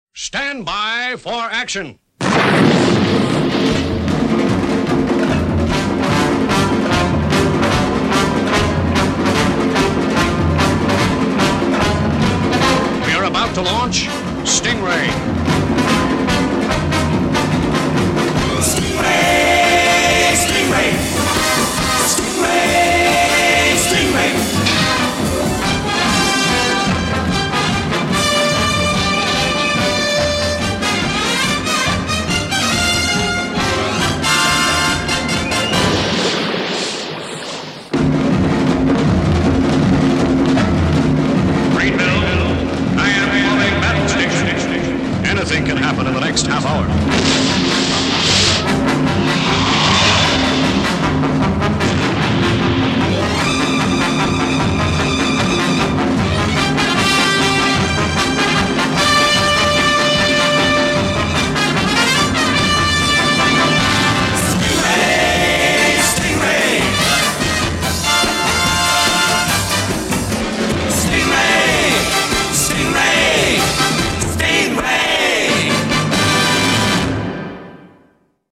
Une B.O. kitsch, bien sûr, mais dans le bon sens du terme.